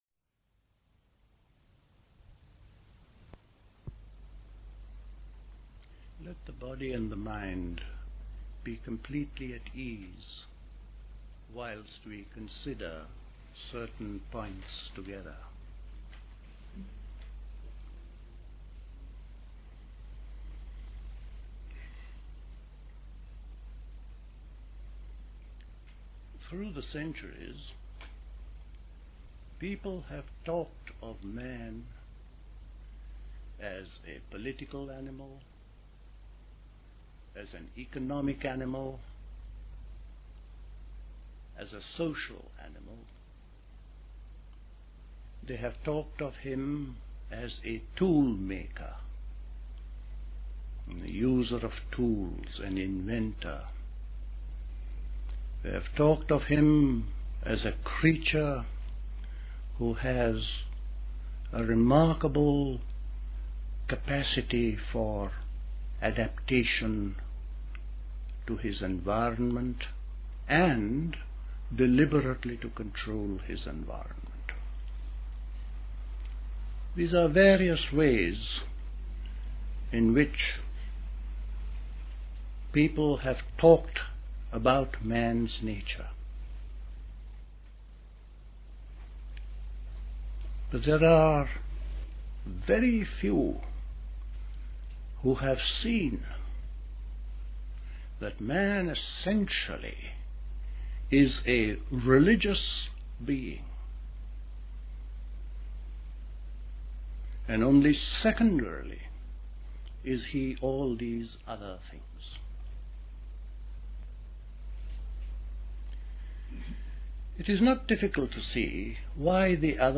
The complete reversal of worldliness. Nature evolves organisms, it is our job to bring Mind to maturity. A meditation: enter the state of ease — breath rhythm — being aware of the moving stillness and eloquent silence of the life-rhythm.